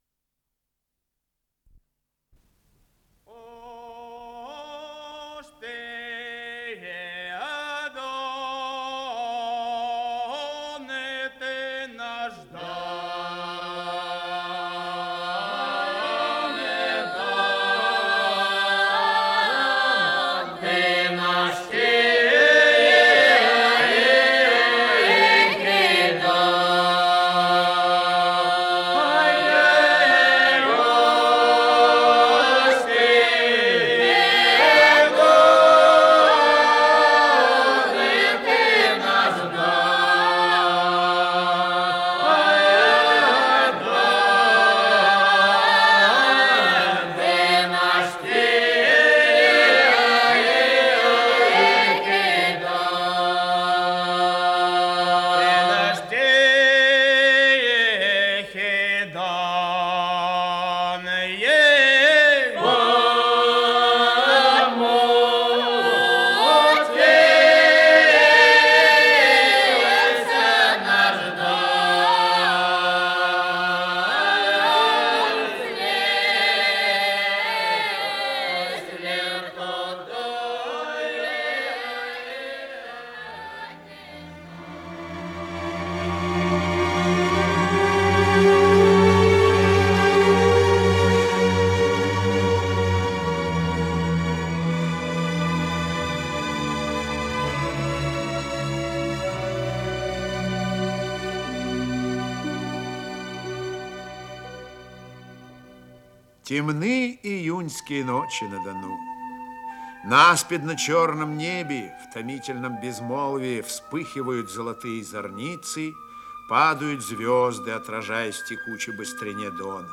Исполнитель: Михаил Ульянов - чтение